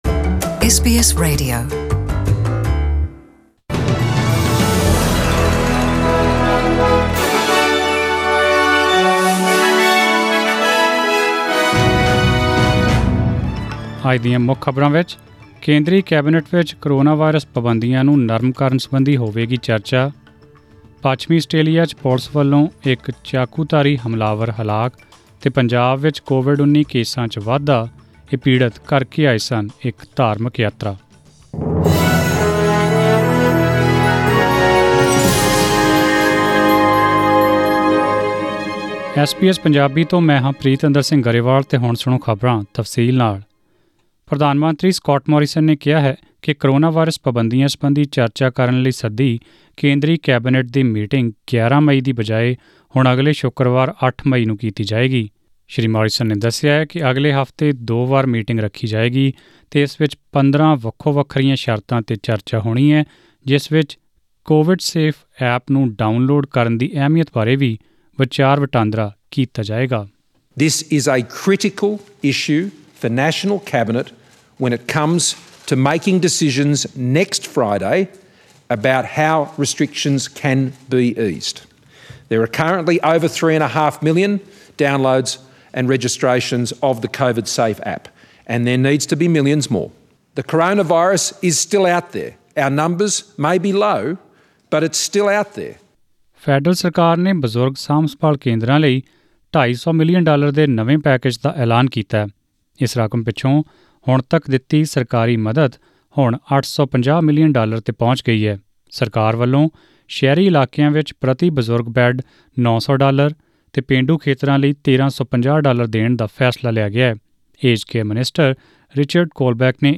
In this bulletin...